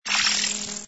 ui_end_scan.wav